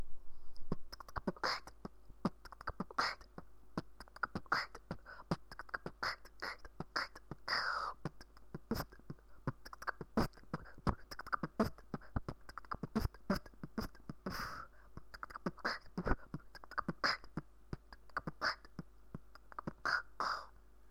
B tktk bKch tb (3 раза; хендклеп можно заменять на пуфф)
B tktk b Kcht Khct BKcht
говорю сразу - бит записан ужасно)
на мой взгляд, хорошо сбалансированный бит)
Хендклеп слабоват из-за записи, а так вроде нормальный, а вот быстрые хеты точно тренировать нужно, знаю.